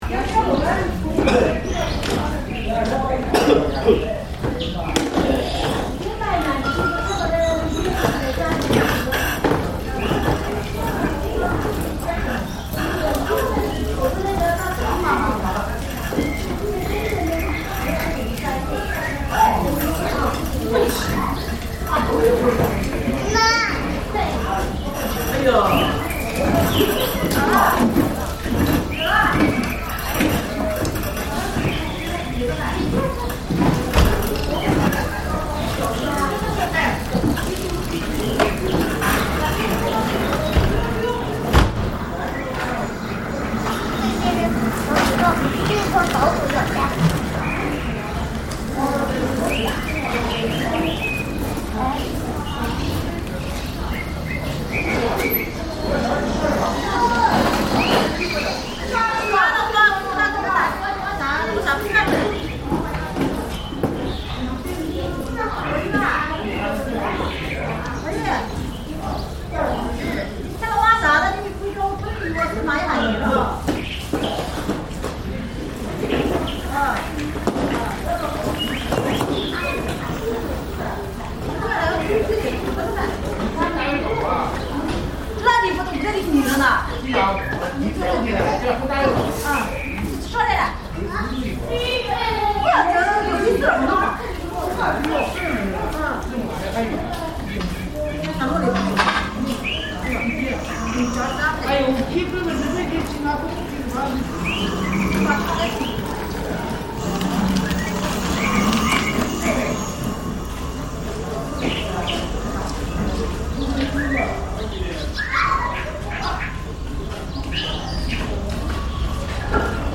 I arrived during breakfast time, with few vehicles around. Shopkeepers sauntered to open their stores, and kids were nudged to school. Local women spoke loudly, sounding like they were arguing, but were just chatting about daily life. Without the hum of scooters, this scene could belong to any era past.